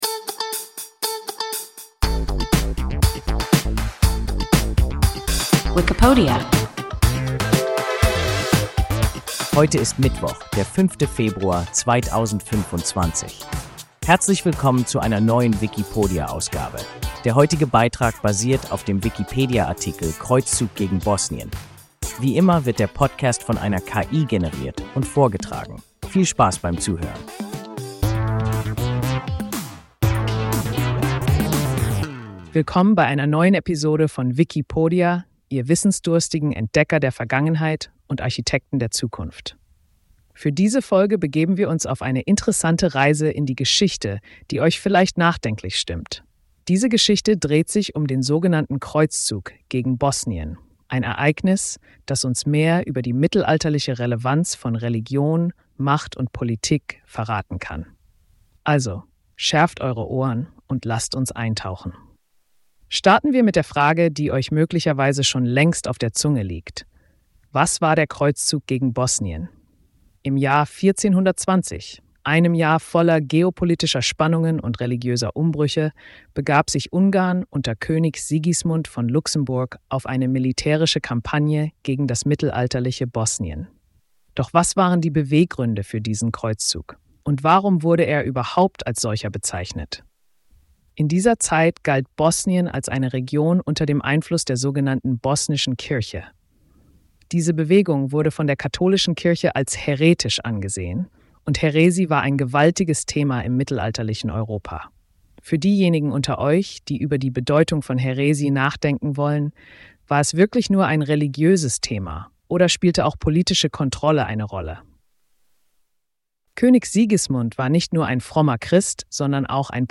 Kreuzzug gegen Bosnien – WIKIPODIA – ein KI Podcast